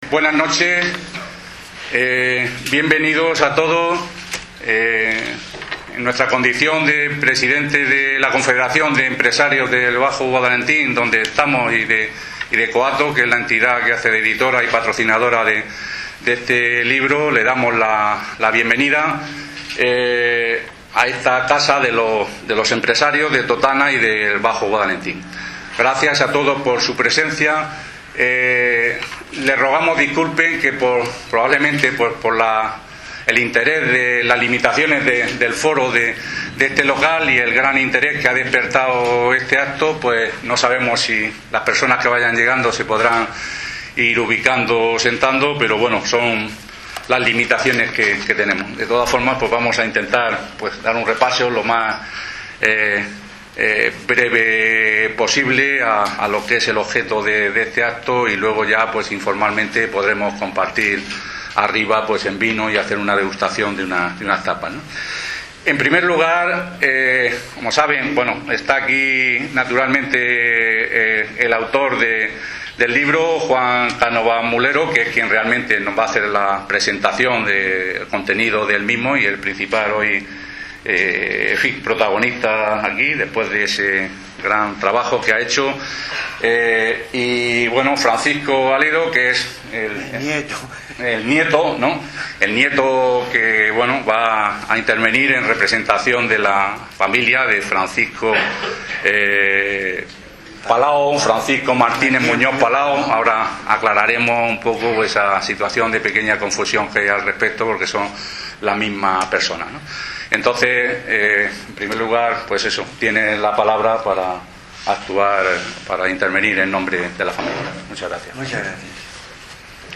Se celebró en el salón de actos de CEBAG